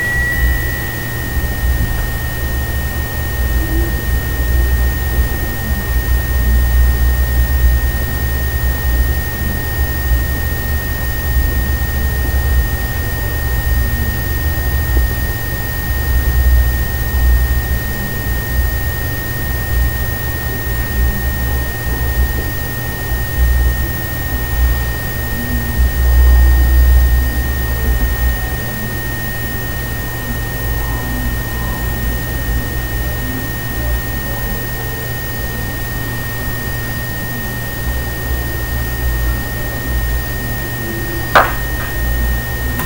ultrasound tone projection speak thinking original recording
This recording above was done with a normal microphone.
ultrasound-tone-projection-speak-thinking-original-recording.mp3